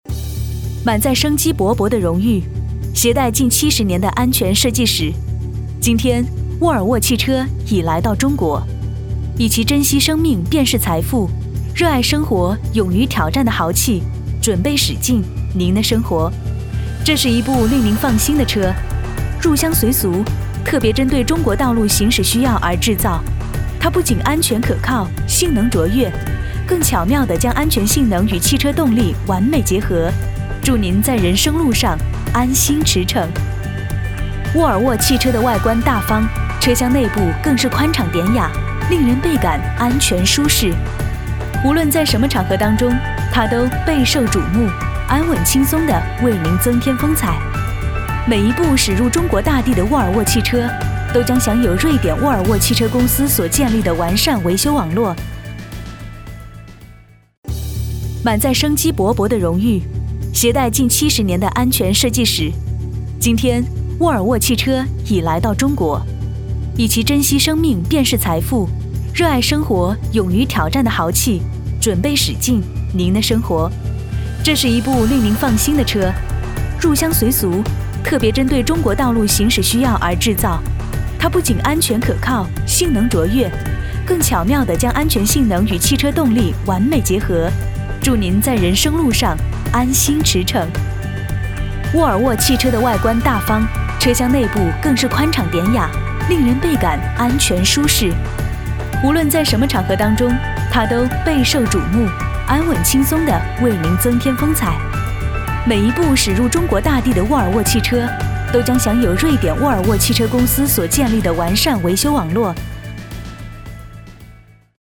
• 女S19 国语 女声 广告-沃尔沃-时尚汽车广告-动感 活力 激情激昂|积极向上|时尚活力|亲切甜美